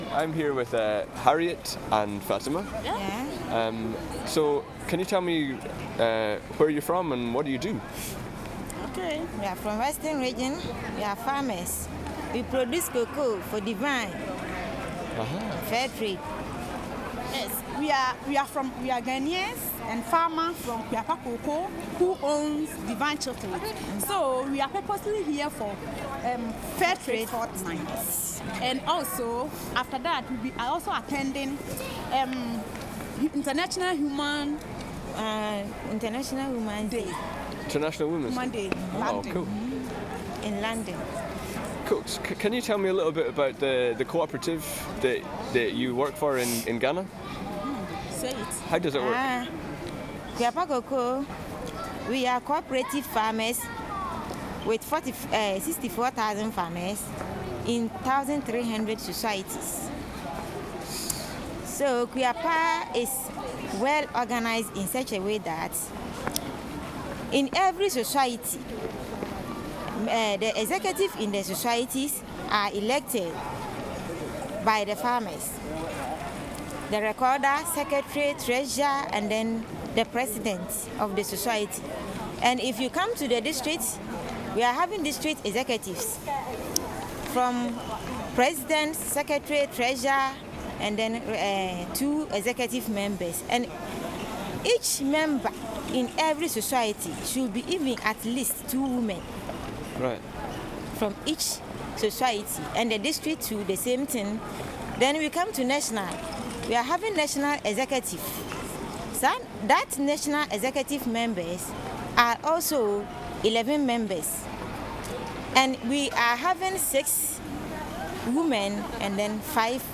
(11 minutes 17 seconds) Click on a thumbnail for a slideshow view The audio is a bit rough and the video got eaten by the computer, but picture a cloudy, rain spattered, and somewhat chilly day in a grim Northern Town, in the presence of two workers who until a day or two had never been on an aeroplane, an escalator, nor even seen a Dalek[ 4 ], and you'll have the basic idea. Topics covered include organisation of their workers' co-operative - the 64 000 farmers organised into 1300 societies, the structure of each of those societies and the national executive (including gender representation), the uses to which the fair trade premium obtained for their produce is used (construction projects - schools, boreholes, evening classes, machinery, research and development, training for farmers.. )